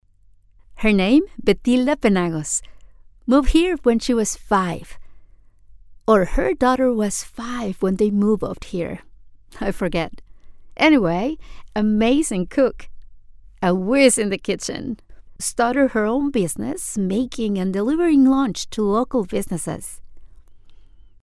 Latin American voice over English accent